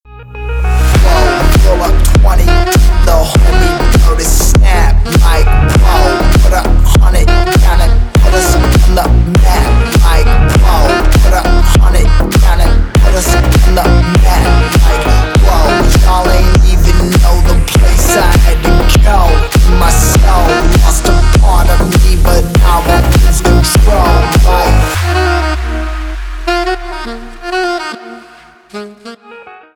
Поп Музыка
Танцевальные
клубные